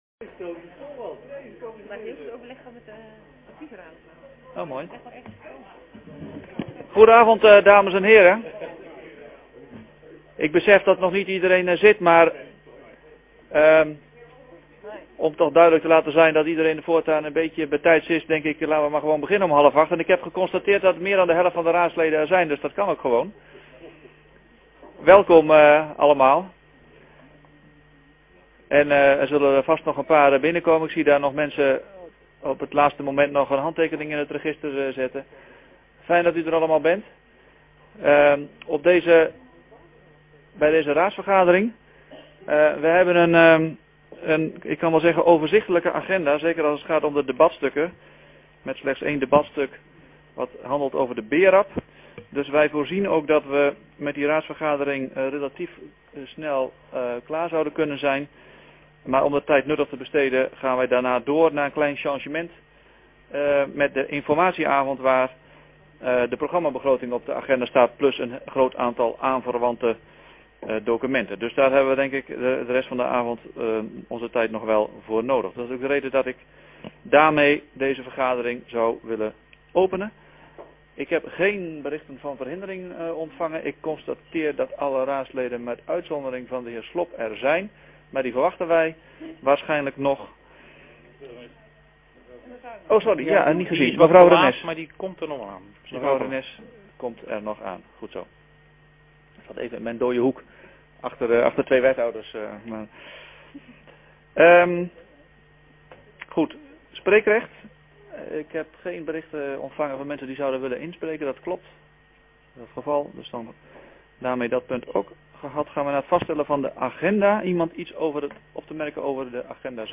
Hoornaar, gemeentehuis - raadzaal